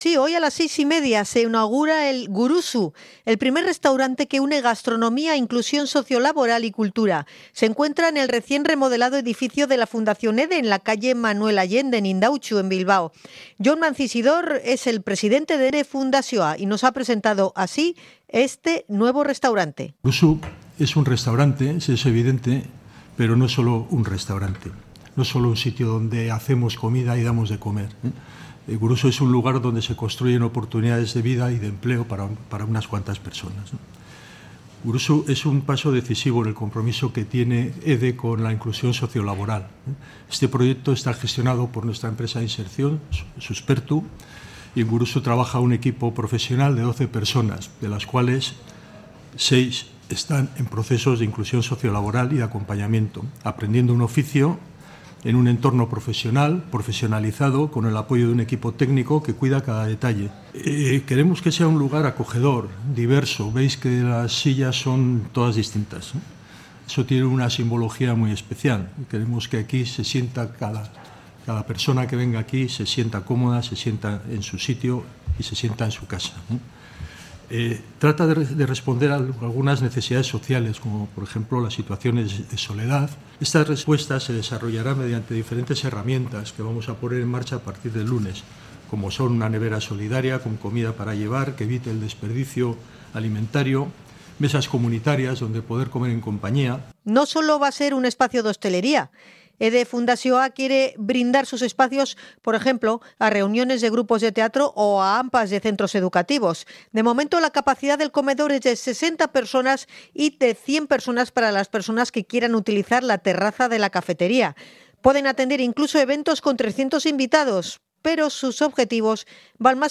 Hemos estado en la pre-inauguración del restaurante, en la calle Manuel Allende